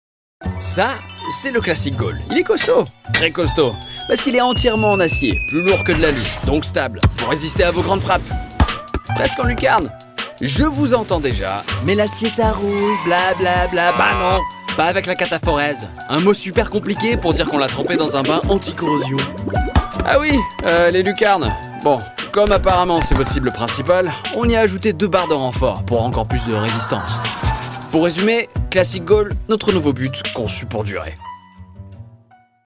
MALE VOICE OVER DEMOS AND EXTRACTS
Commercial DECATHLON